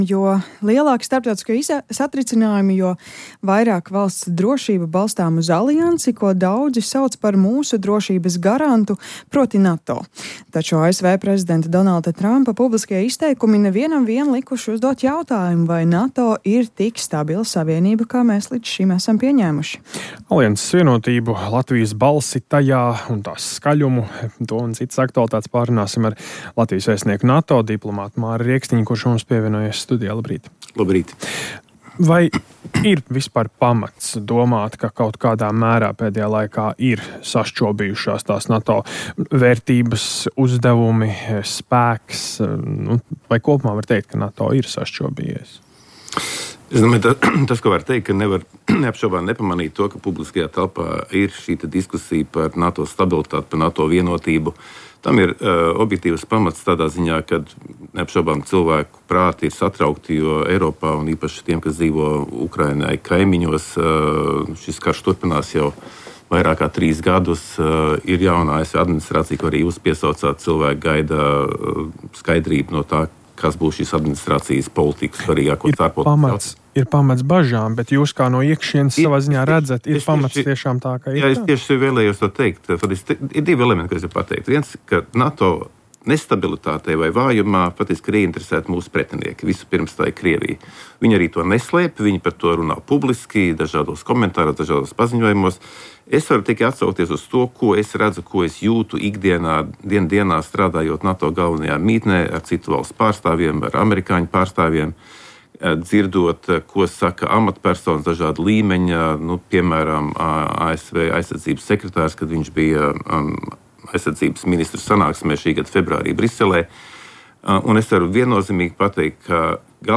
Ivars Ījabs: Eiropa ir pusatmodas stāvoklī - Rīta intervija - Podcast